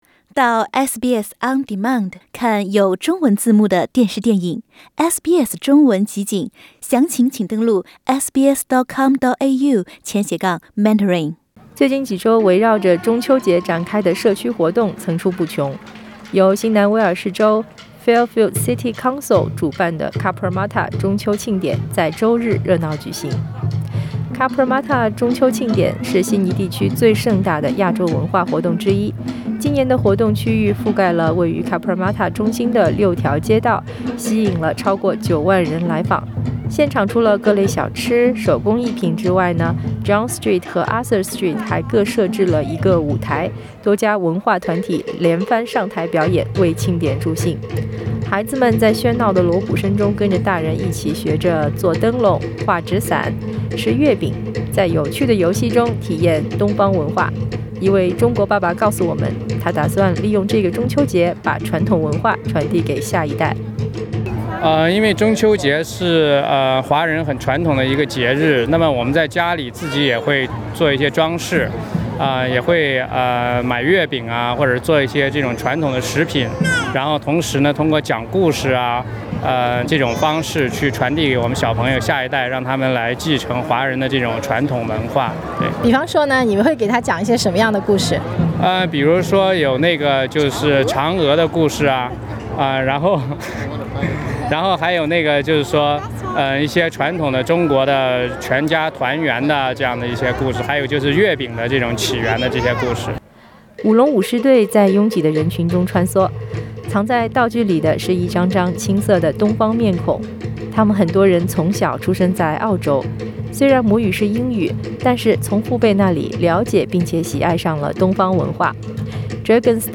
由新南威爾士州Fairfield city council主辦的Cabramatta中秋慶典在週日熱鬧舉行。和往年一樣，我們SBS radio也前去和聽眾朋友們交流互動。